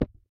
default_dig_oddly_breakable_by_hand.ogg